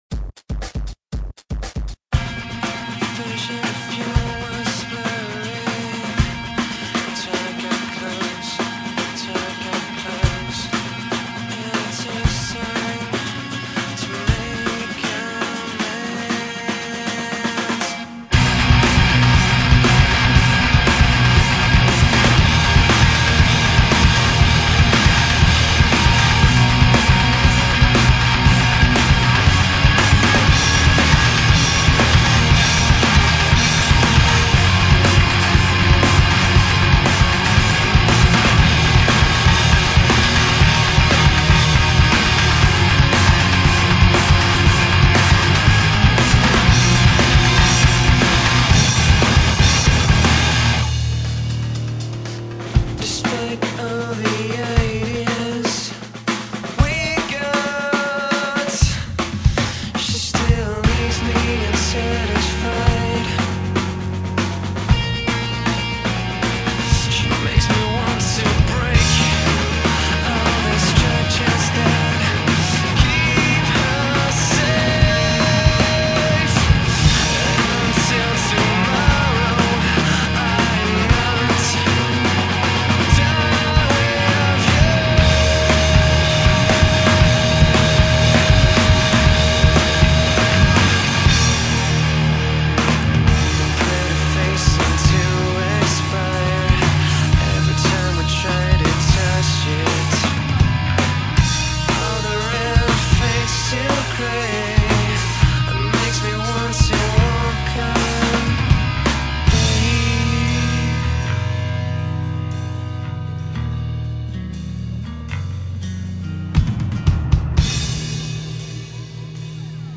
Rock
manipulant break, effet de voix, changement de rythme...